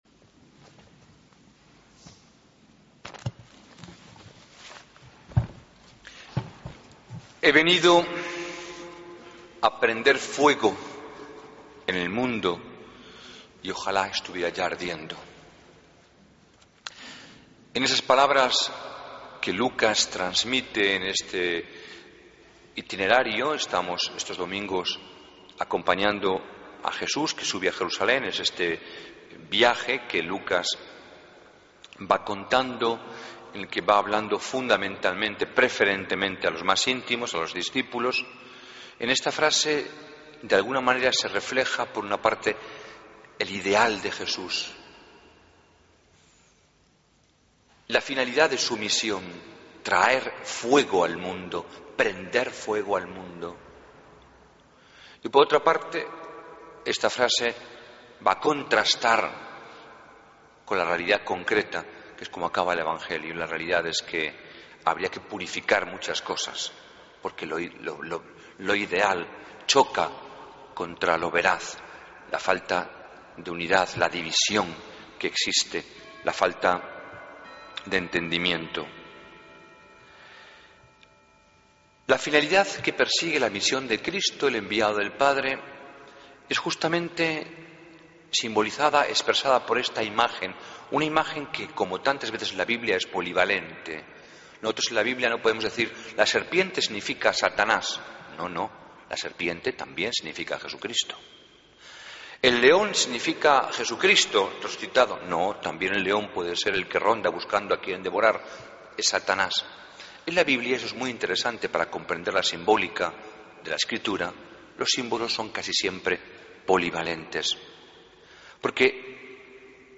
Homilía del 18 de agosto de 2013